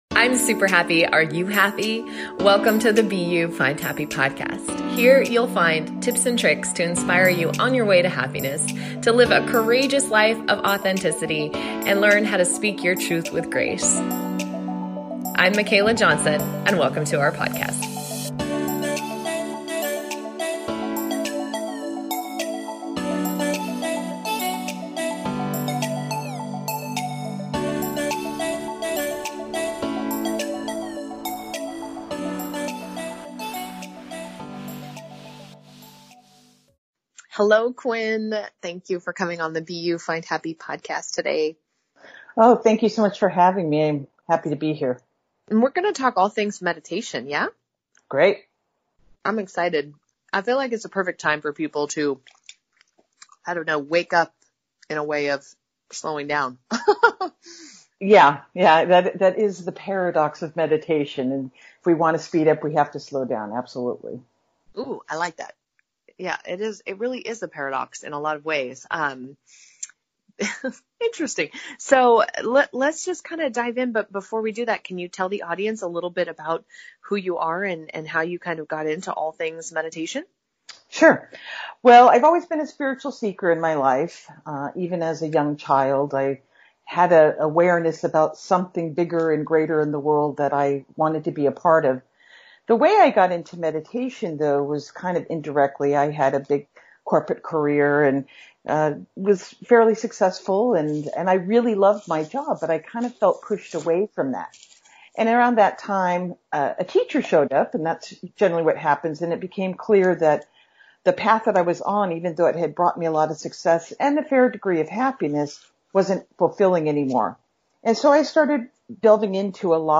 In this episode, she leads a guided meditation.
Talk Show